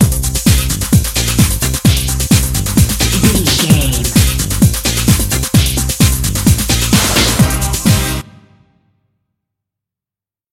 Aeolian/Minor
Fast
drum machine
synthesiser
electric piano
bass guitar
conga
Eurodance